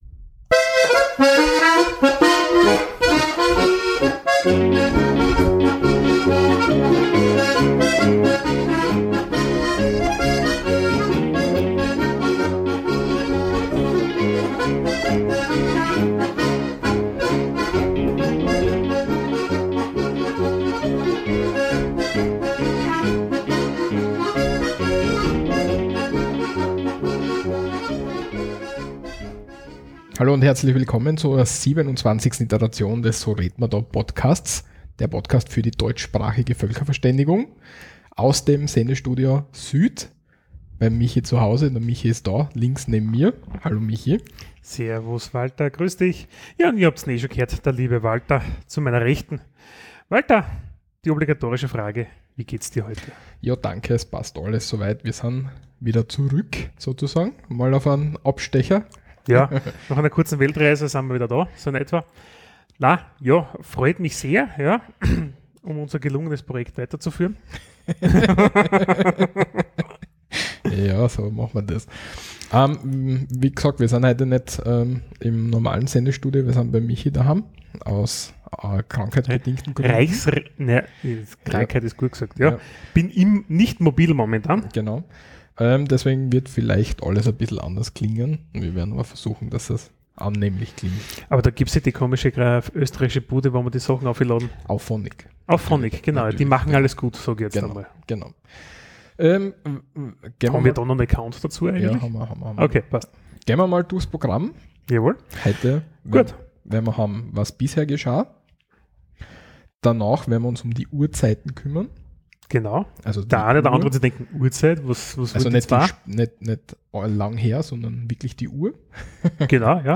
Diesmal melden wir uns aus dem Sendestudio Süd mit unserer Betrachtung von Uhrzeiten im deutschsprachigen Raum sowie die Urzeit Österreichs nämlich dem Kaisertum in Österreich.